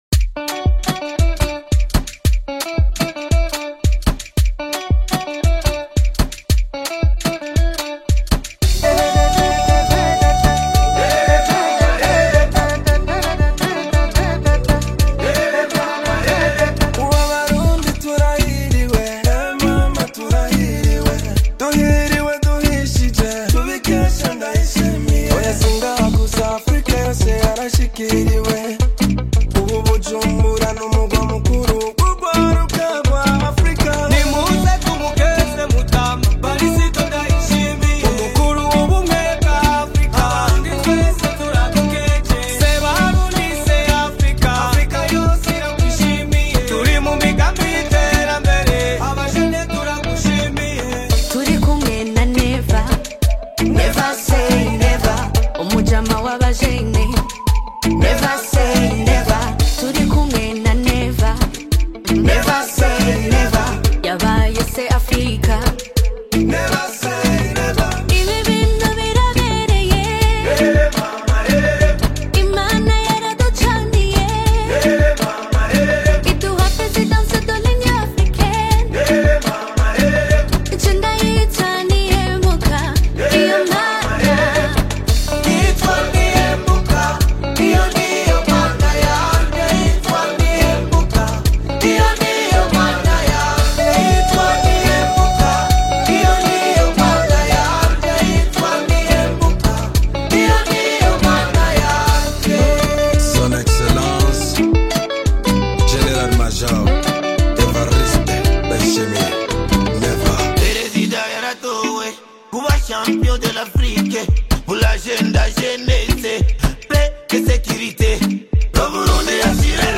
AfrobeatBurundian Music
collaborative love anthem
With smooth production and infectious melodies